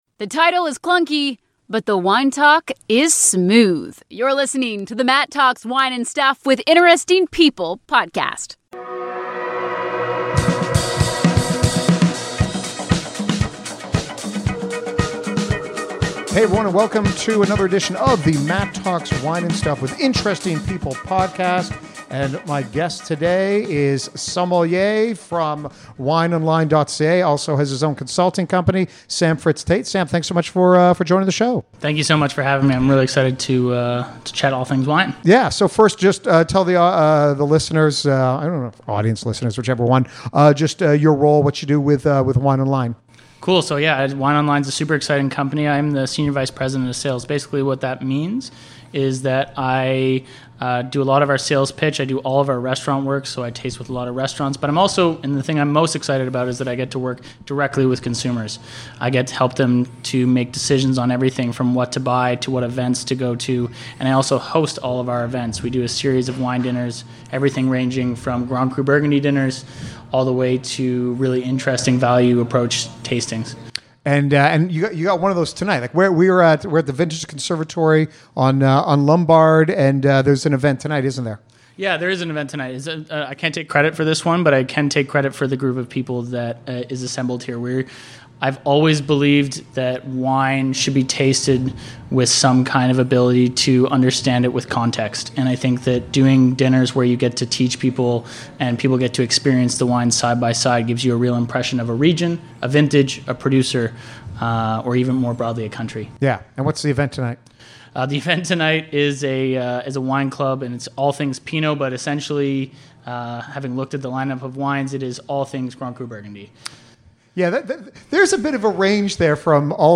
A wide ranging conversation about the dangers of wine reviews, dirty tricks done by large scale wineries, what is the better grape: Pinot Noir or Northern Rhone Syrah and issues with the marketing of “Natural Wine”.